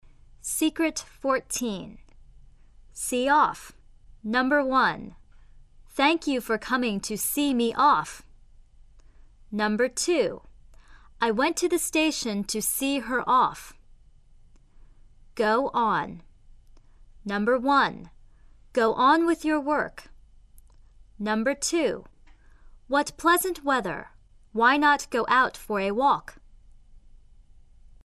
（2）字尾元音+字首元音